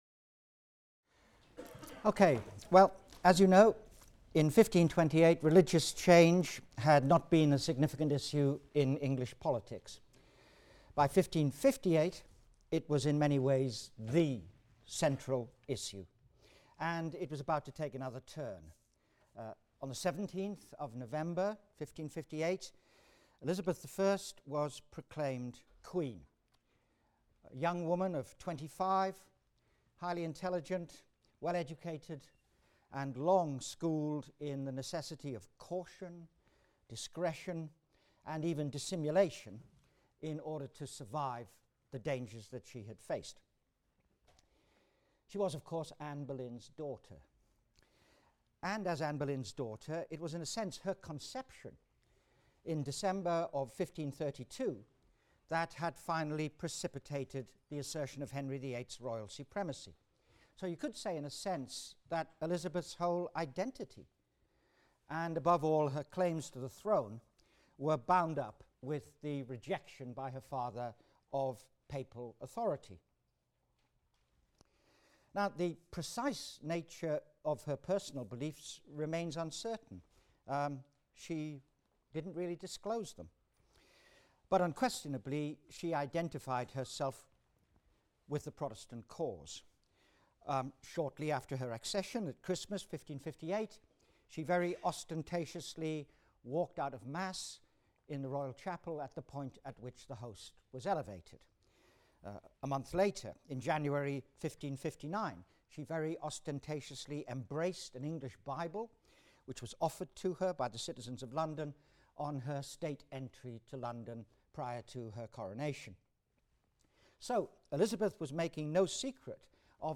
HIST 251 - Lecture 10 - The Elizabethan Confessional State: Conformity, Papists and Puritans | Open Yale Courses